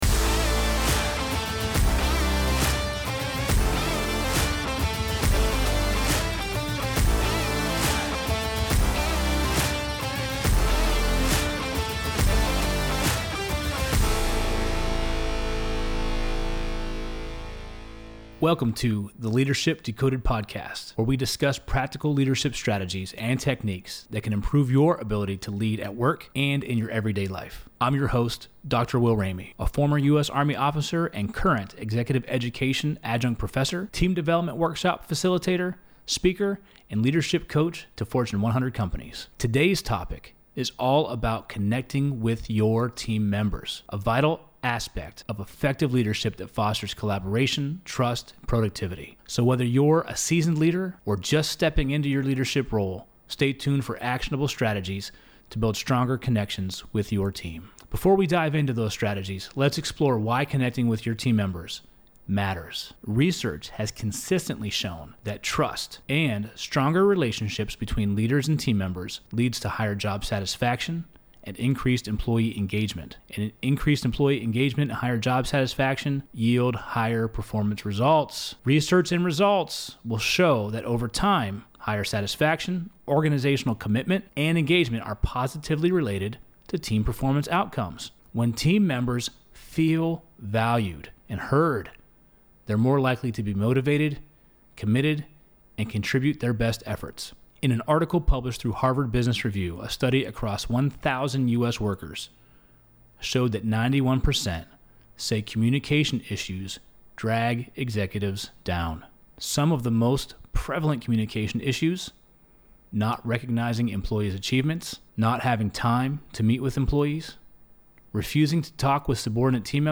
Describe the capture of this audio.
in the Loop Internet studio